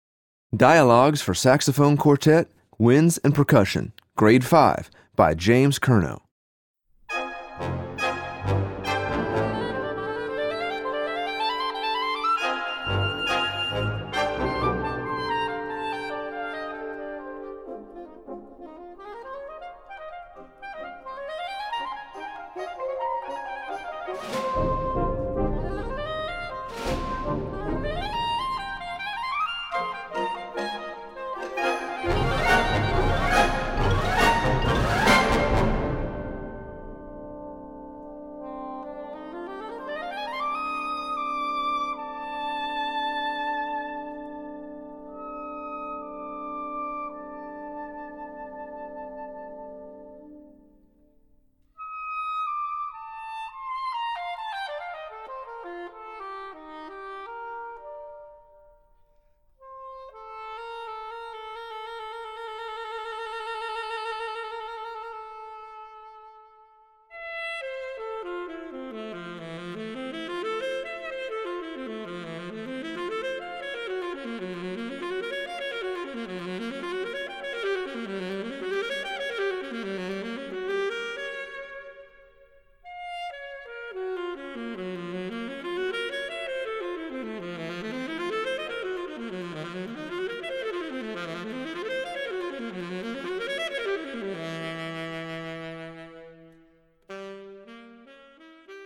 Voicing: Saxophone Quartet w/ Band